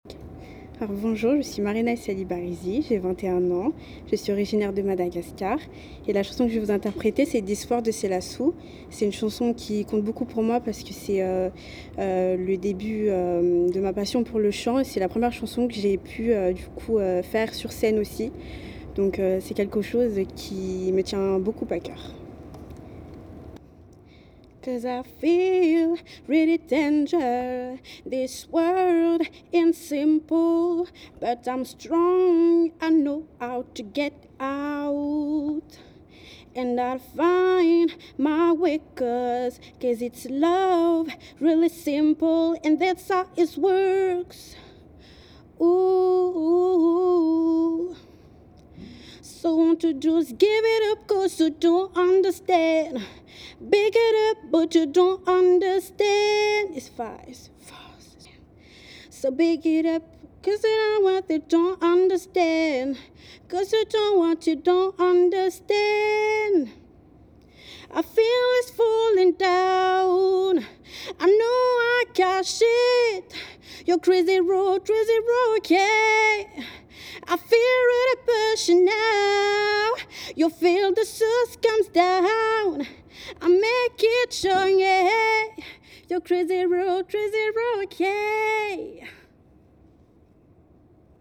chant en anglais